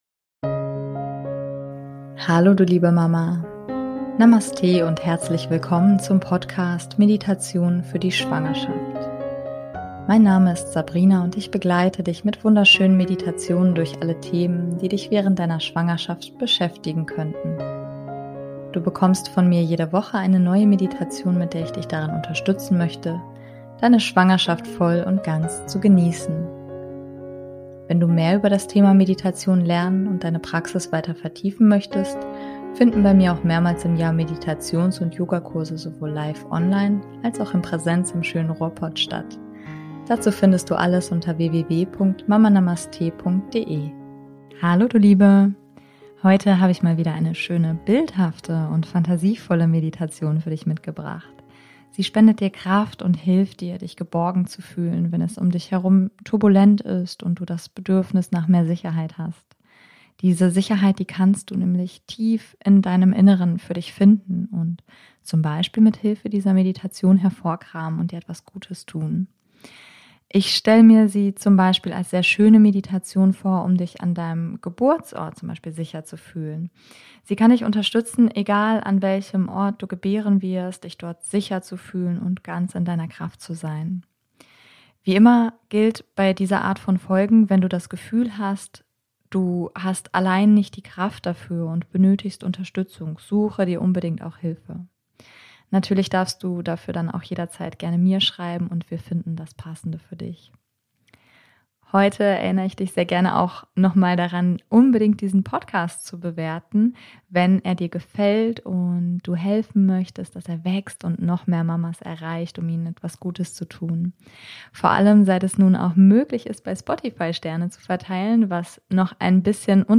Heute habe ich mal wieder eine schöne bildhafte und etwas fantasievolle Meditation für dich mitgebracht. Sie spendet dir Kraft und hilft dir, dich geborgen zu fühlen, wenn es um dich herum turbulent ist und du das Bedürfnis nach Sicherheit hast.